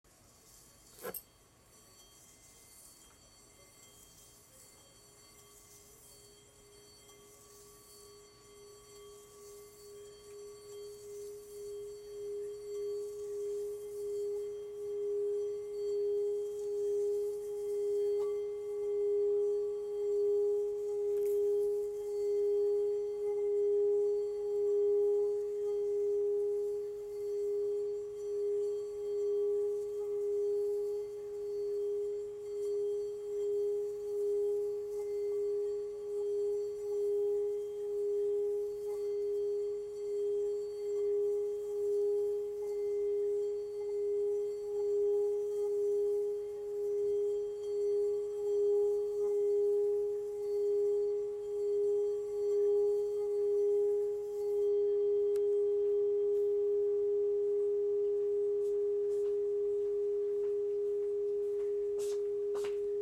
Bol chantant tibétain
Ecoutez les vibrations du bol tibétain gravé Ohm avec un maillet de 28 mm de diamètre:
Les vibrations régulières se produisent au bout de 30 sec environ
vibration-bol-grave.m4a